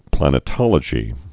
(plănĭ-tŏlə-jē)